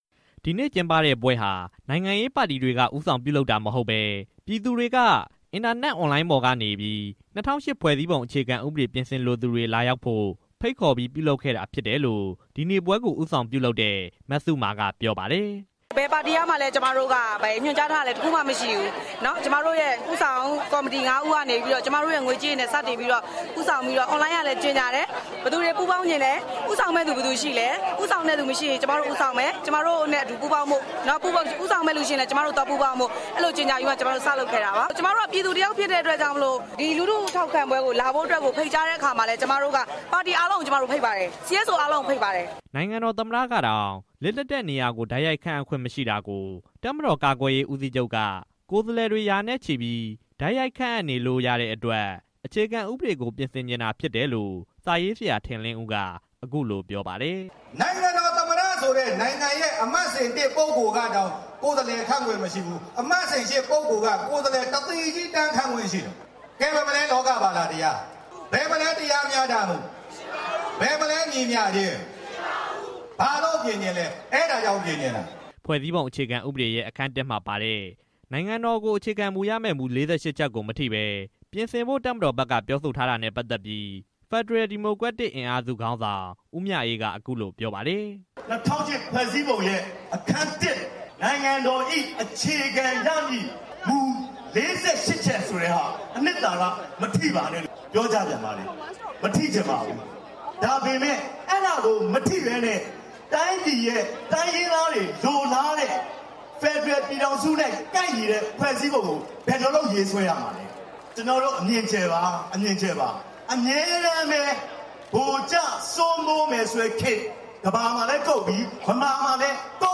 နိုင်ငံ့အနာဂတ်လှပရေး ၂၀၀၈ ခြေဥပြင်ဆင်ပေး၊ ပြည်သူ့အသံ လွှတ်တော်ထံ၊ ပြည်သူ့လိုအပ်ချက် ပြည်သူ့လိုအပ်ချက် ဖြည့်စွက်ပေး၊ ၂၀၀၈ ခြေဥပြင်ဆင်ဖို့ ပြည်သူအားလုံးတောင်းဆိုစို့ စတဲ့ကြွေးကြော်သံတွေ ကြွေးကြော်ခဲ့ကြပါတယ်။